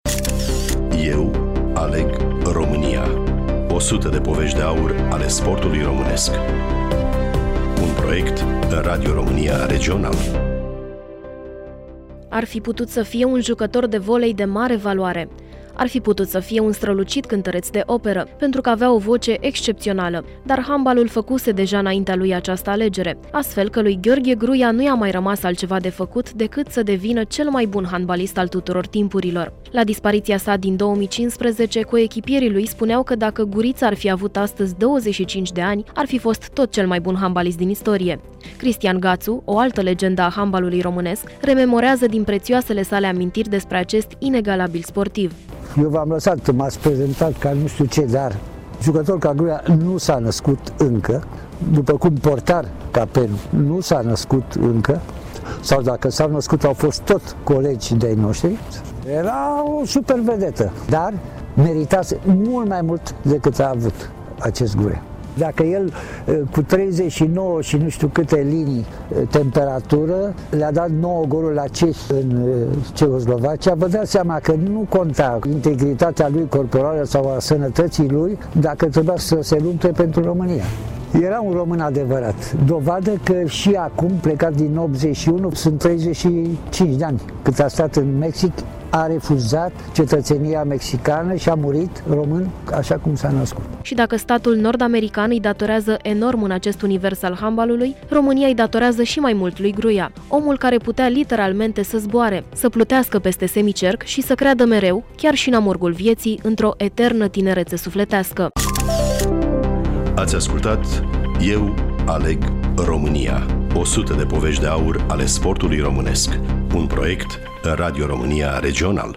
Cristian Gațu, o altă legendă a handbalului românesc, rememorează din prețioasele sale amintiri despre acest inegalabil sportiv.
Studioul: Bucuresti FM